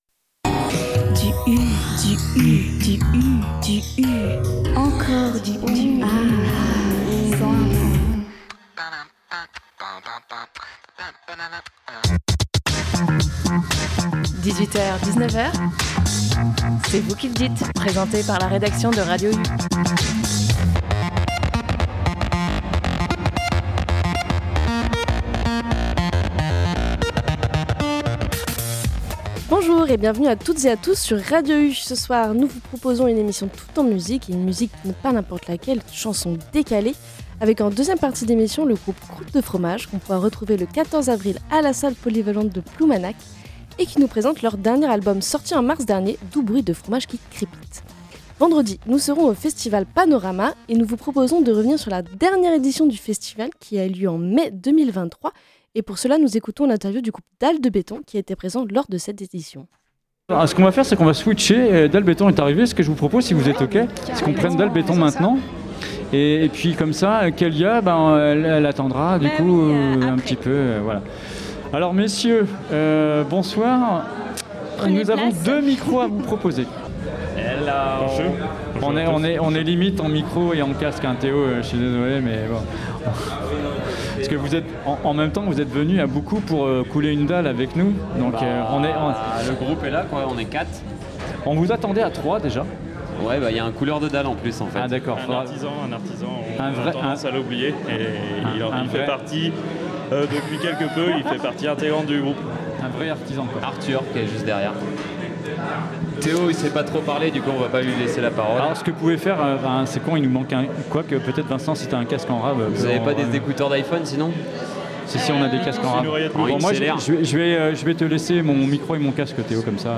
Dans cette première émission du mois d’Avril, nous revenions en première partie sur la dernière édition du festival Panoramas, en prévision de notre venue pour l’édition 2025, avec une interview du groupe Dalle béton.
Puis nous avons accueilli un autre groupe de musique au nom bien particulier : Croûte de fromage pour une interview toute aussi particulière...